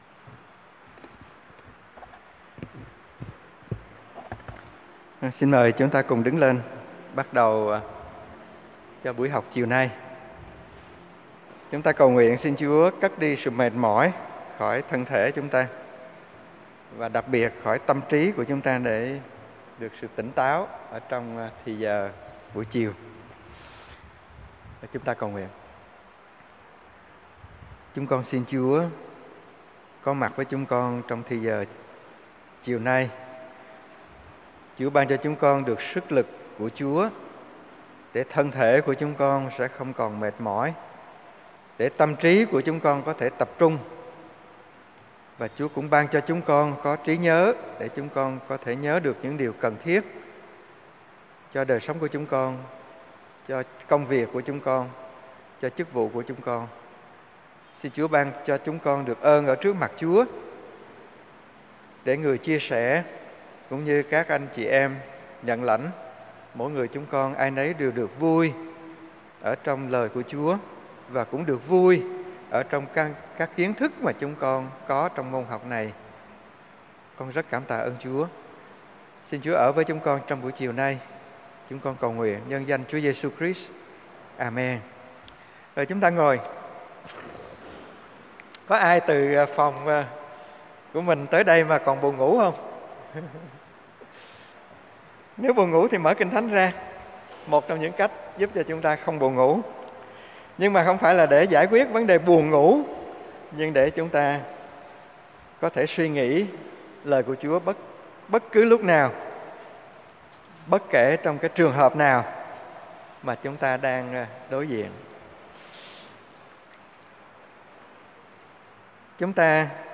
Tổng Quan Kinh Thánh T5 VL - Bài giảng Tin Lành